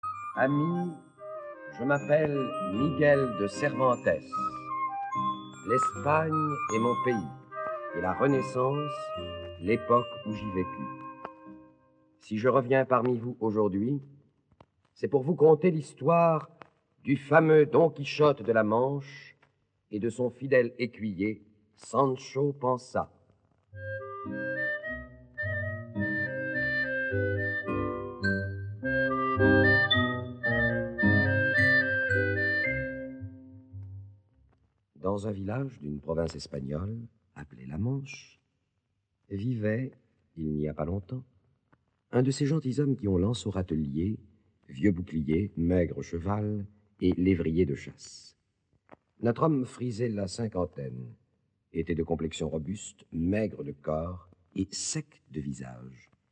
enregistrement original de 1954